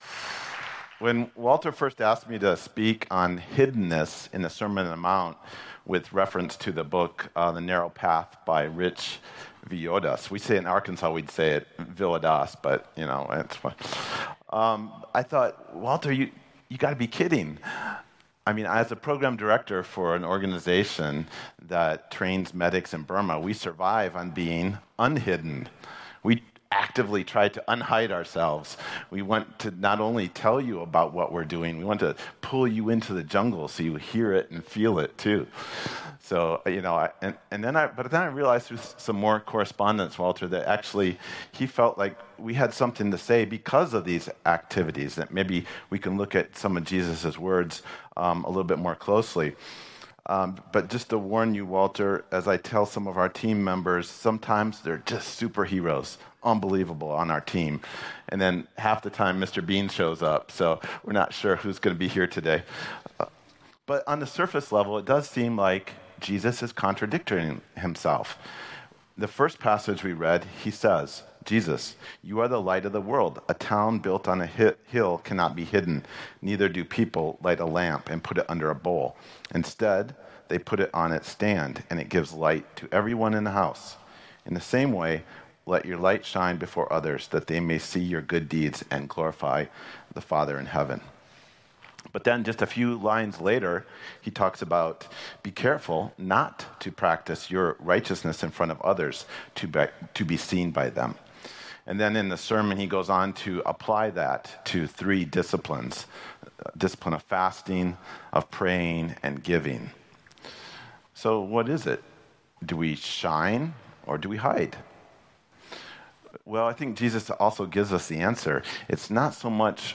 The Narrow Way: Hiddenness (Sermon 6/22/2025)